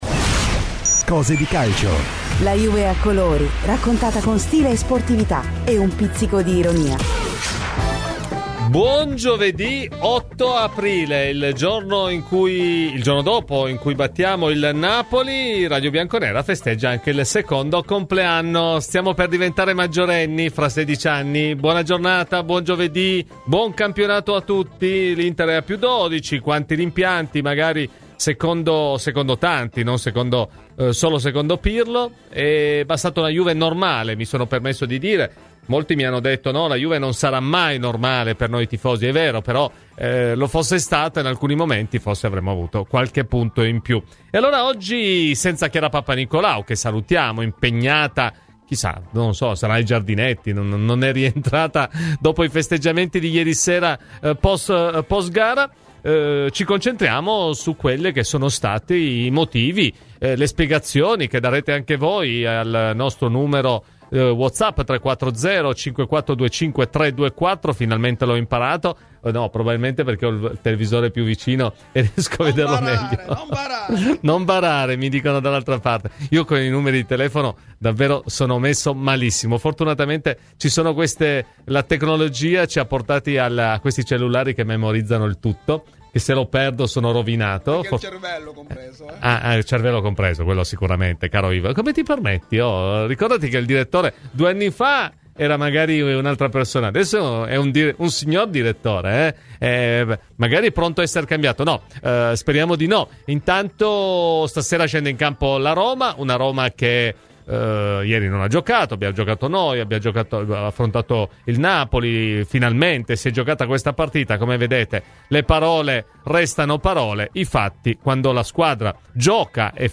Clicca sul podcast in calce per ascoltare la trasmissione integrale.